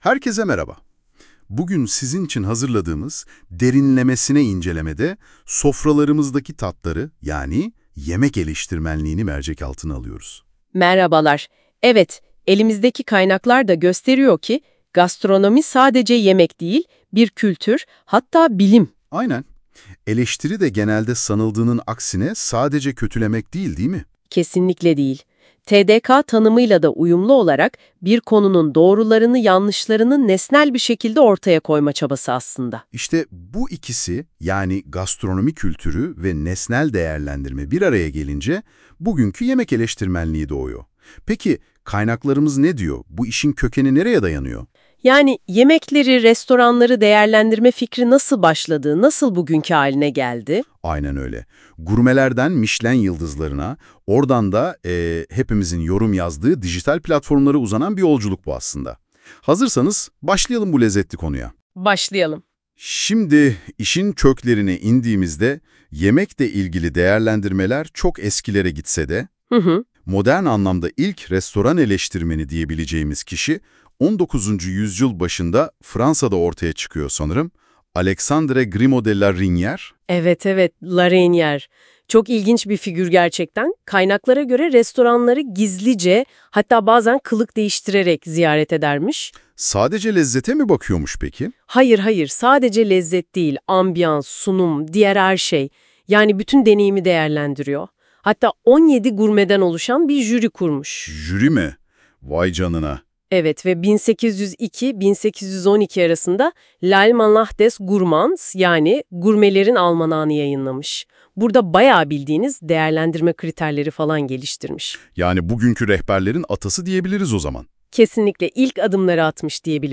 Gıda Eleştirmenliği Üzerine Söyleşi